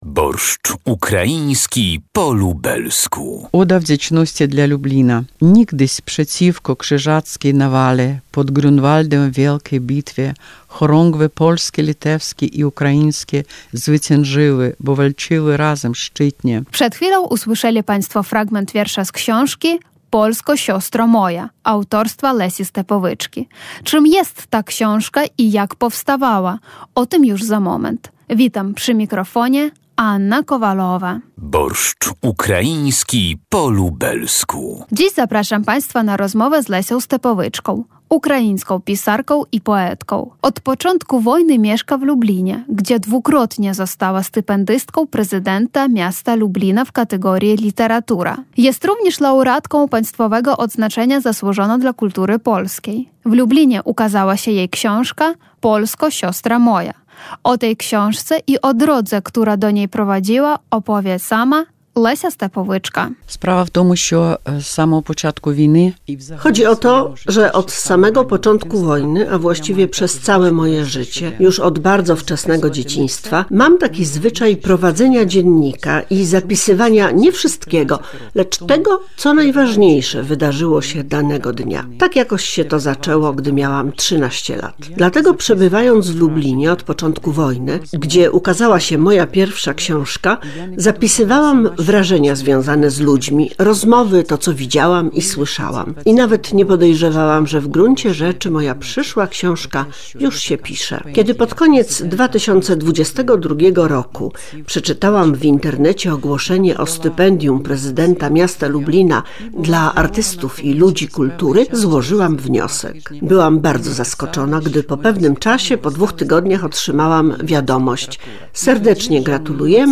Przed chwilą usłyszeli Państwo fragment wiersza z książki „Polsko, siostro moja”